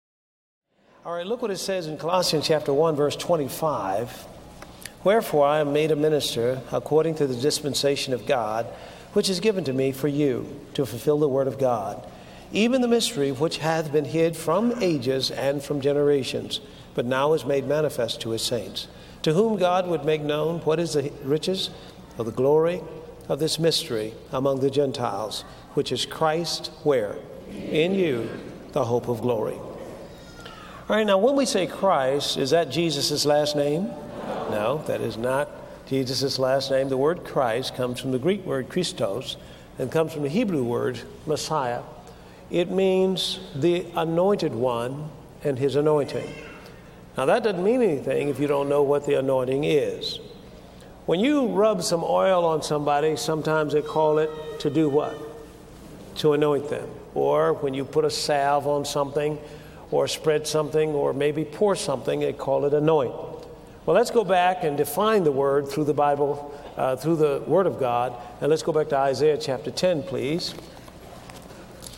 (4 Teachings) In this powerful teaching